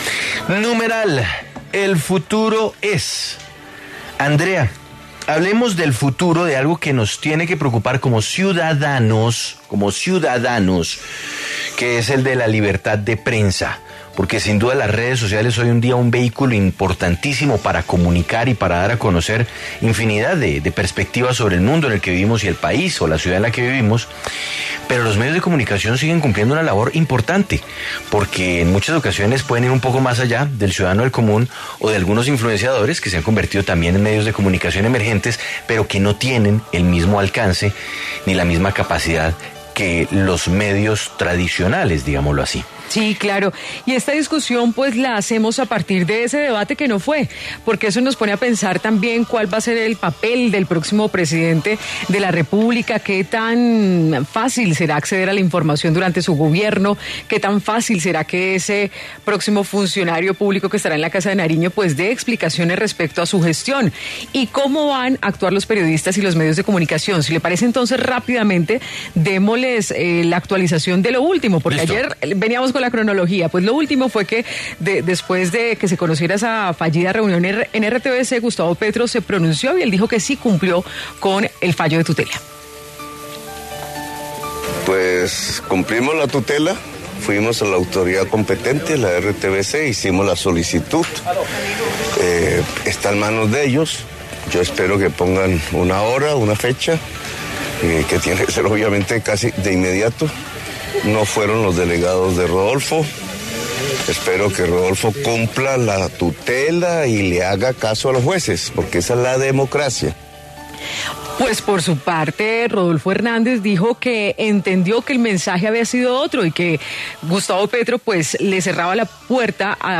De cara a las elecciones del próximo 19 de junio, Sigue La W conversó con periodistas sobre los retos y el papel que tendría la prensa en el próximo gobierno.